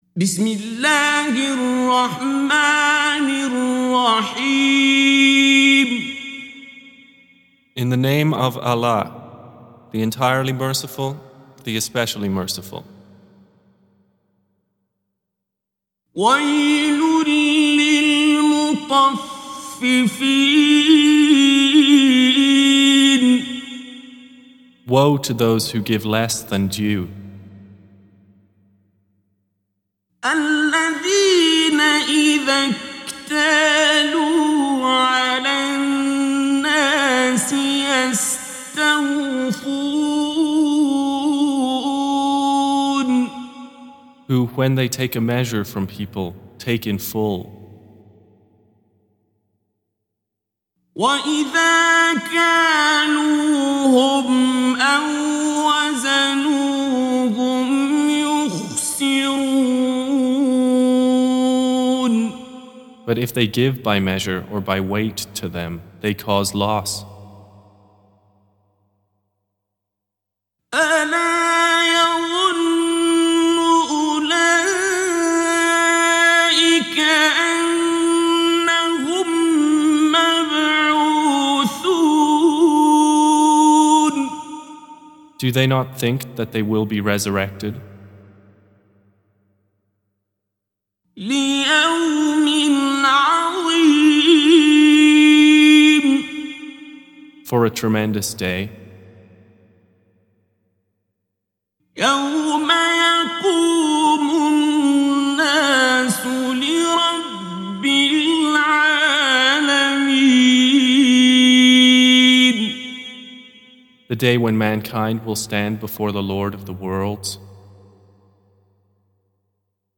Surah Repeating تكرار السورة Download Surah حمّل السورة Reciting Mutarjamah Translation Audio for 83. Surah Al-Mutaffif�n سورة المطفّفين N.B *Surah Includes Al-Basmalah Reciters Sequents تتابع التلاوات Reciters Repeats تكرار التلاوات